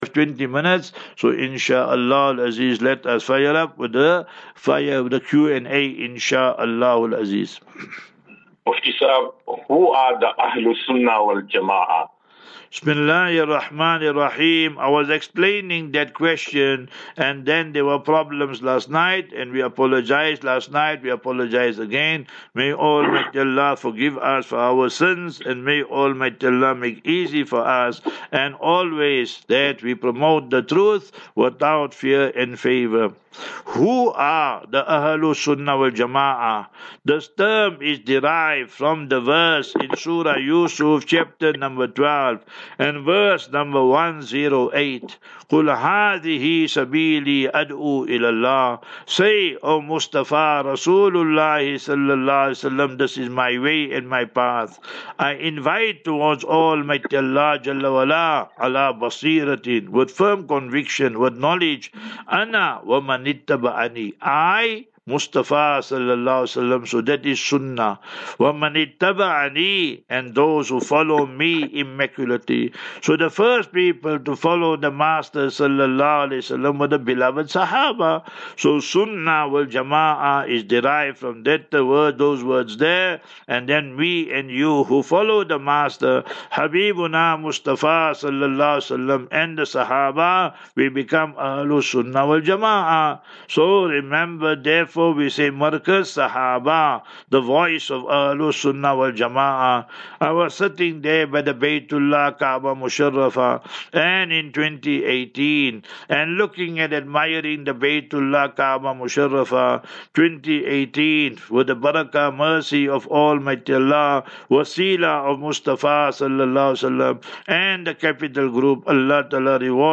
Part 2. QnA